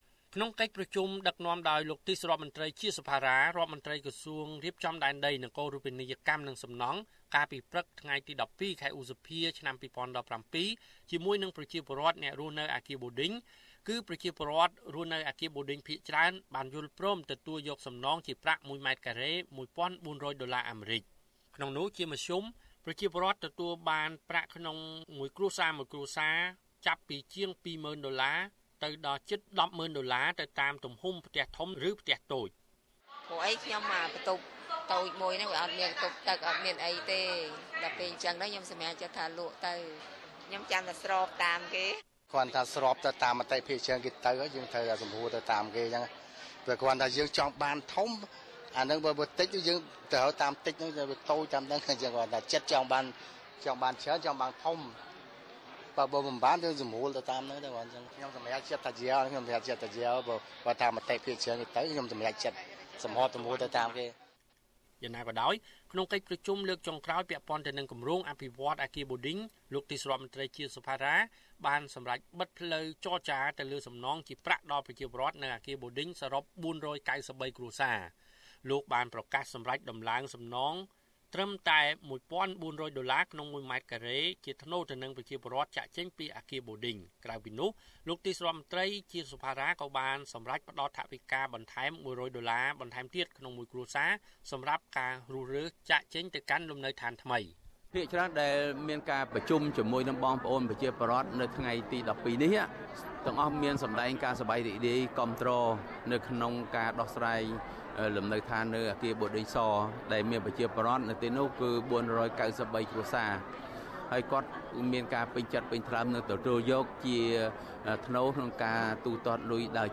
រាយការណ៍លំអិត។